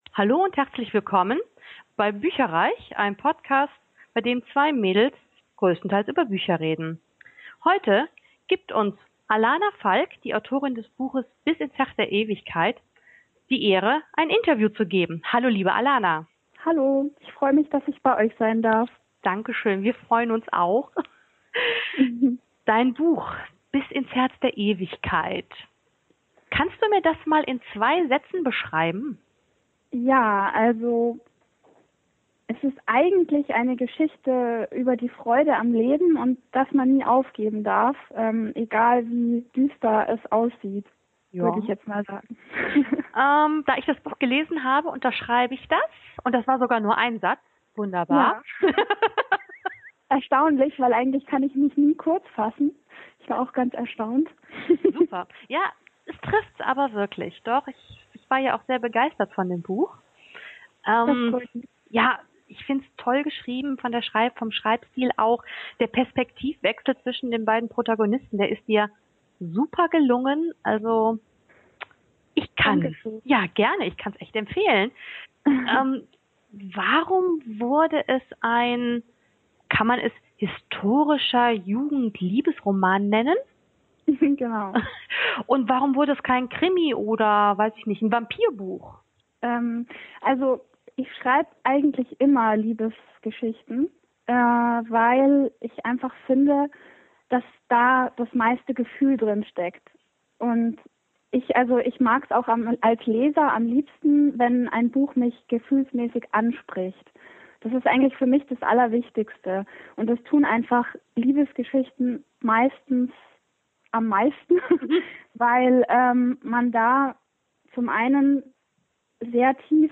Beschreibung vor 11 Jahren Willkommen bei bücherreich, unserem Bücher-Podcast!
*Entschuldigt bitte, dass die Tonqualität leider nicht auf unserem üblichen Niveau ist, da das Interview via Skype geführt und aufgenommen wurde.